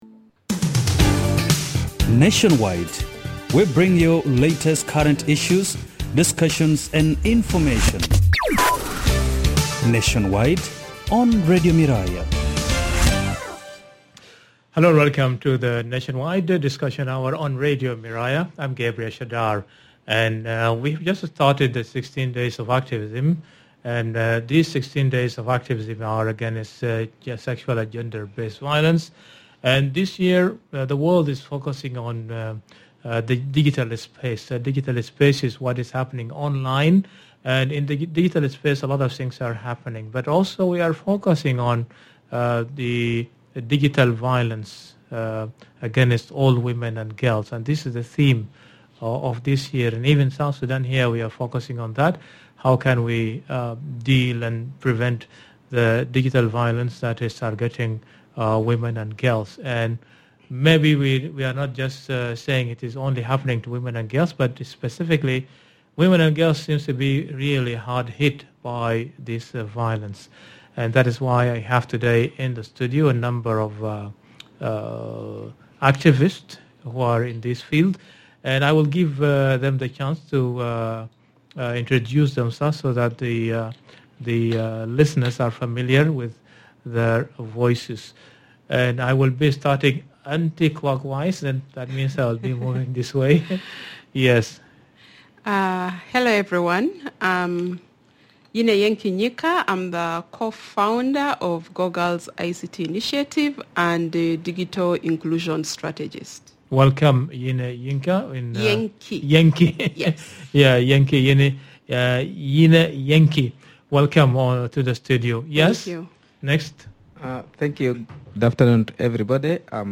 This episode of Nationwide explores the rising threat of digital violence against women and girls in South Sudan as part of the 16 Days of Activism. A panel of activists highlights how online spaces are increasingly used for harassment, body-shaming, intimidation, stalking, and even trafficking.
They call for stronger legal frameworks, digital safety education, responsible online behaviour, and collective community action. In the studio